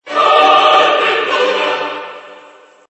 Efectos de sonido / Videojuegos
WORMS HOLY GRENADE es un Tono para tu CELULAR que puedes usar también como efecto de sonido